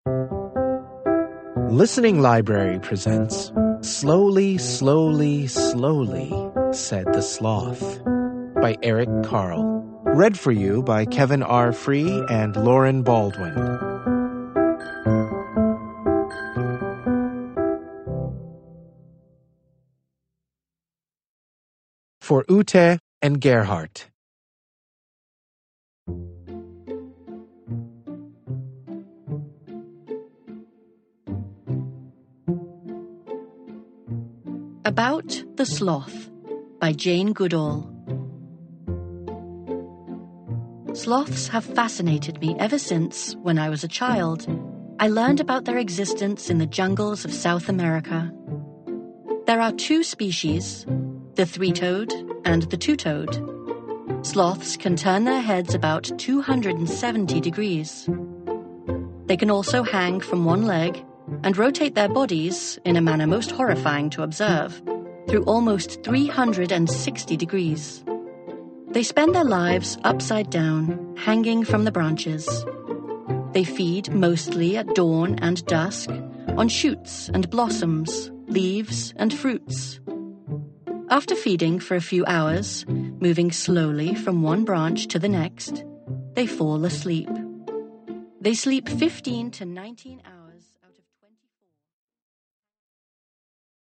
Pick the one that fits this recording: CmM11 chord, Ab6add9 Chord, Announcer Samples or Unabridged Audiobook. Unabridged Audiobook